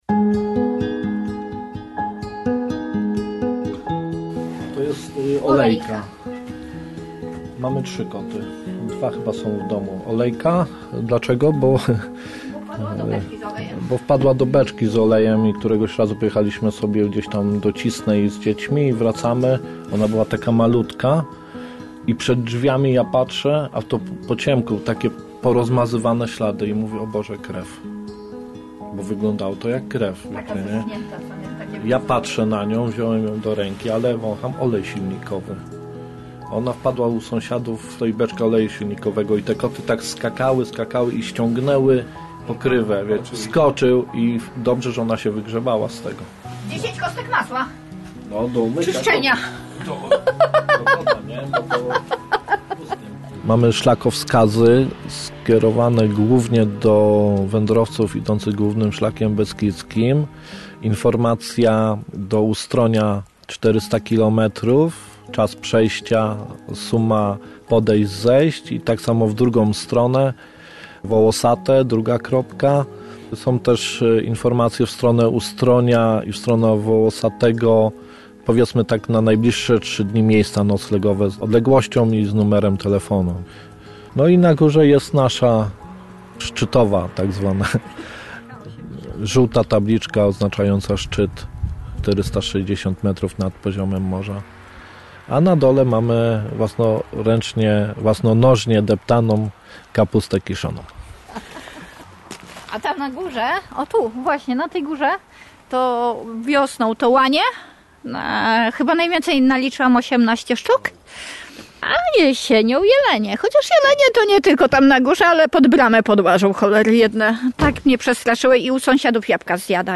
Opowieść o tych, co z miast przeprowadzili się w Bieszczady. Tam przenieśli swoje życie, pracę i osiedlili się wśród nowych ludzi, innej przyrody. Reportaż nie tylko wakacyjny, ale dający refleksję o poszukiwaniu swojego miejsca na ziemi, w zgodzie ze sobą.